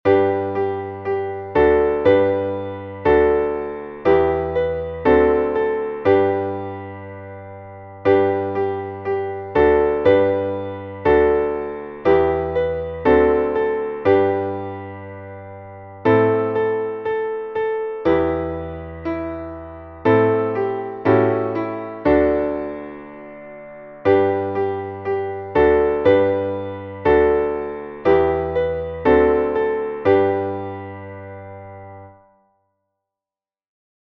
Traditionelles Lied aus Frankreich